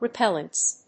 発音記号
• / rɪˈpɛlʌnts(米国英語)
• / rɪˈpelʌnts(英国英語)